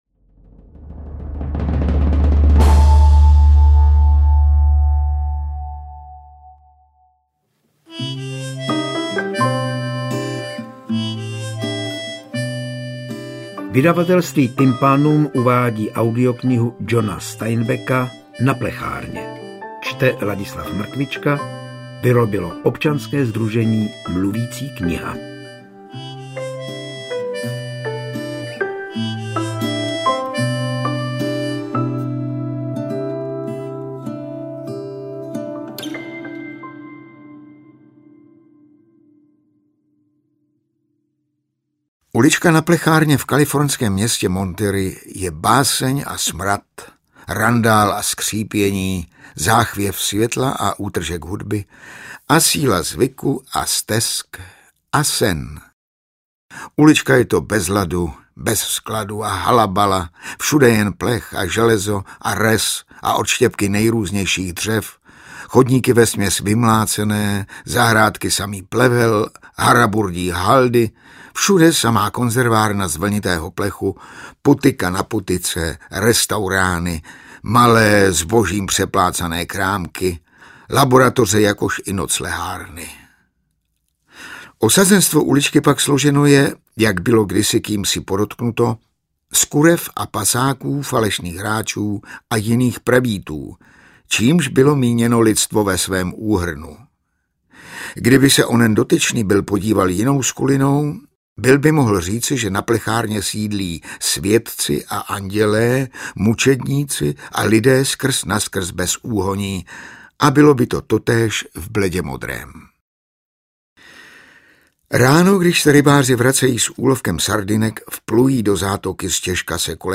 Interpret:  Ladislav Mrkvička
AudioKniha ke stažení, 44 x mp3, délka 6 hod. 59 min., velikost 382,3 MB, česky